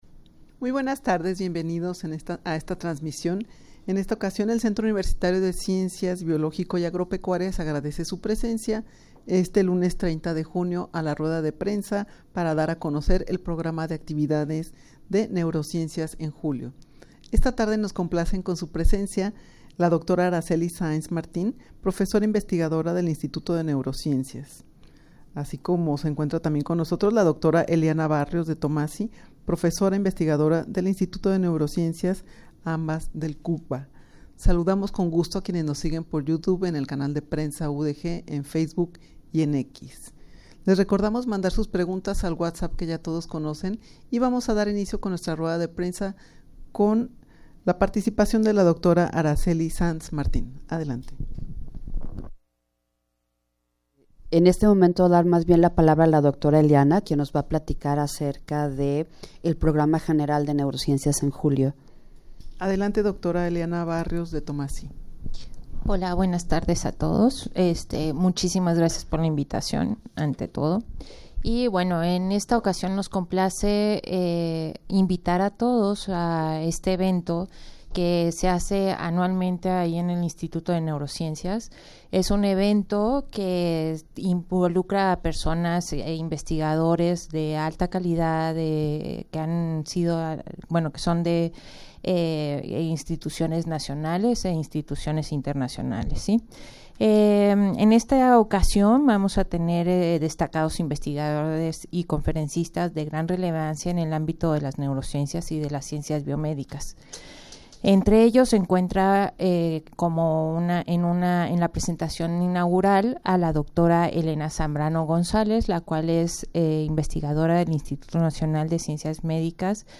rueda-de-prensa-para-dar-a-conocer-el-programa-de-actividades-de-neurociencias-en-julio.mp3